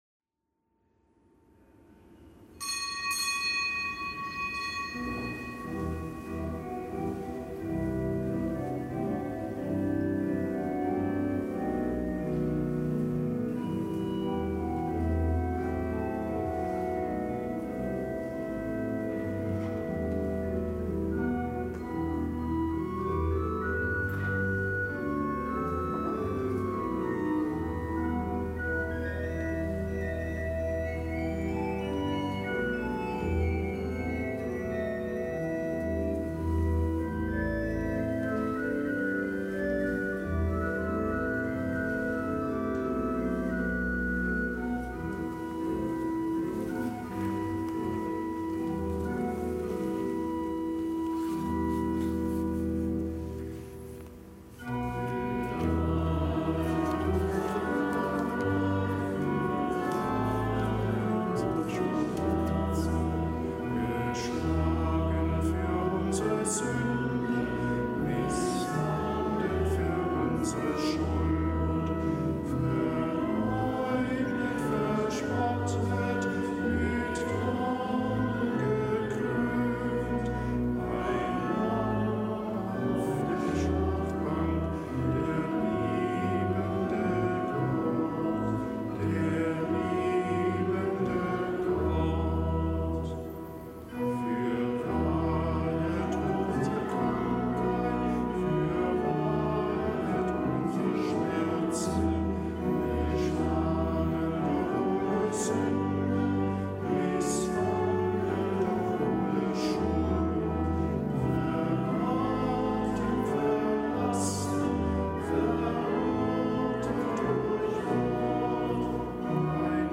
Kapitelsmesse am Dienstag der Karwoche
Drucken Get an embed code Share on Facebook Herunterladen Kapitelsmesse aus dem Kölner Dom am Dienstag der Karwoche